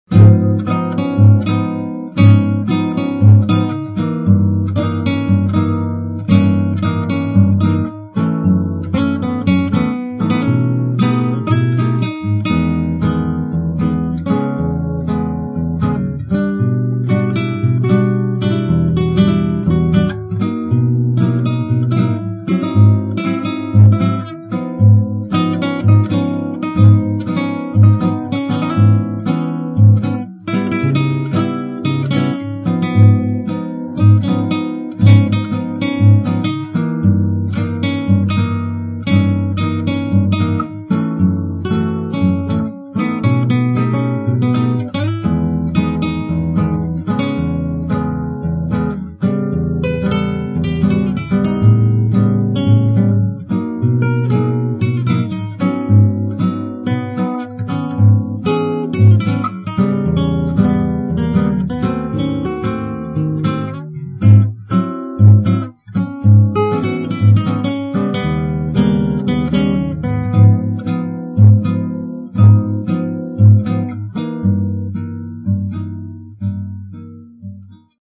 SPAZIO BOSSANOVA
Sostanzialmente identica alla strofa precedente nelle prime otto battute, dalla battuta 9 viene introdotta la variazione che riporta poi al tema principale.